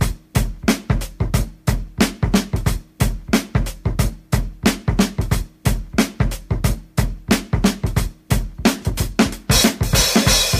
91 Bpm Drum Loop Sample E Key.wav
Free breakbeat sample - kick tuned to the E note.
91-bpm-drum-loop-sample-e-key-Fix.ogg